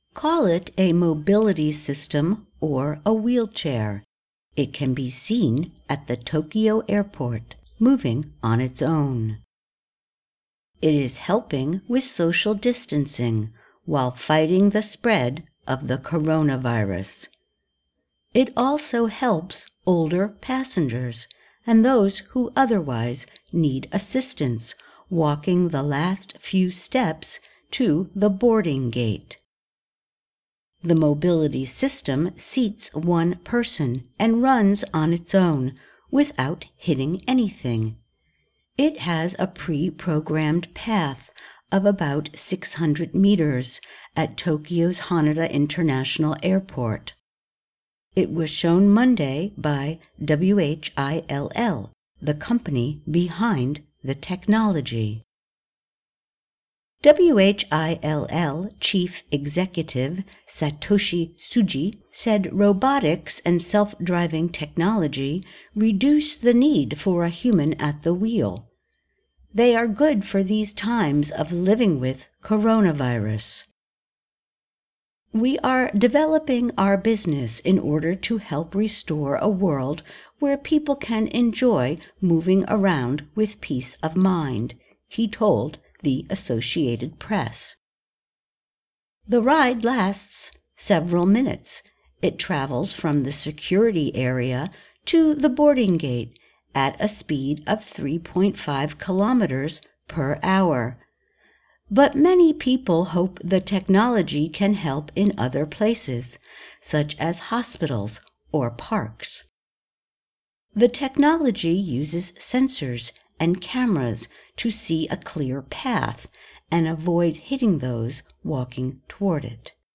慢速英语:东京机场新增智能交通工具